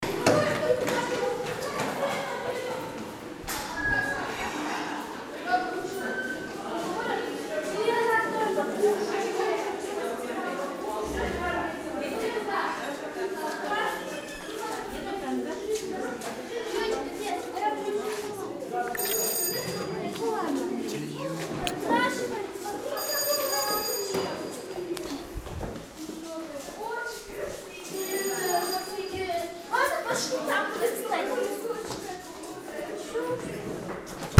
Звуки школьной перемены
Шум болтовни на школьной перемене